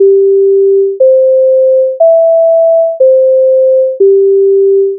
reminder_melody3
reminder_melody3.wav